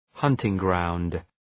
Shkrimi fonetik{‘hʌntıŋ,graʋnd}